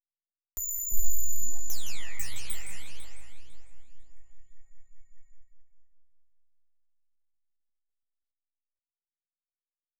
High pitched bleep.wav